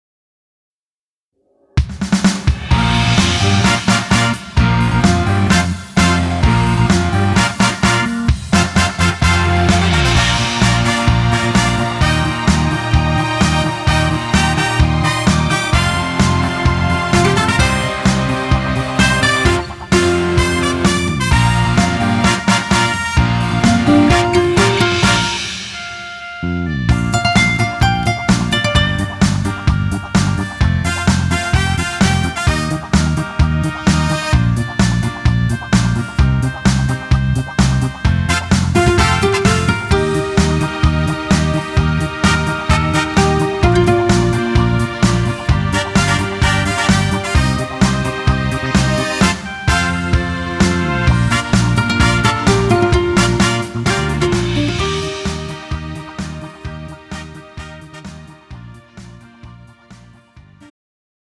음정 원키 3:18
장르 가요 구분 Pro MR
Pro MR은 공연, 축가, 전문 커버 등에 적합한 고음질 반주입니다.